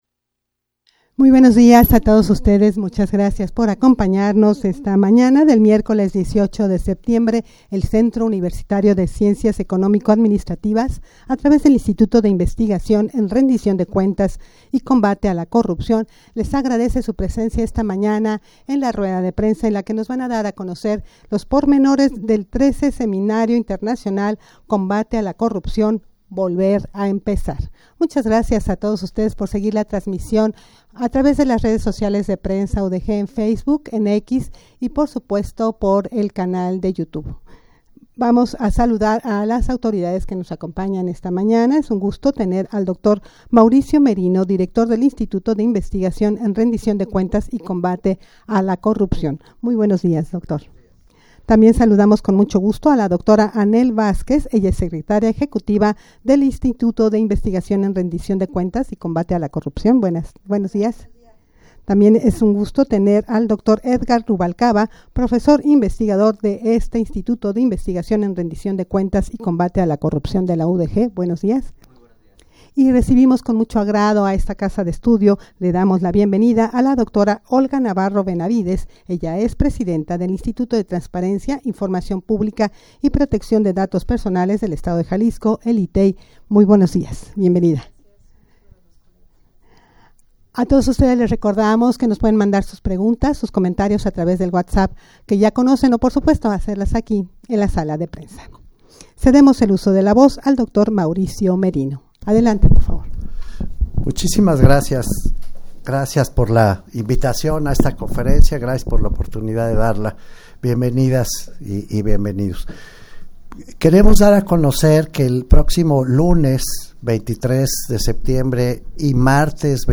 Audio de la Rueda de Prensa
rueda-de-prensa-para-dar-a-conocer-detalles-del-13deg-seminario-internacional-combate-a-la-corrupcion.mp3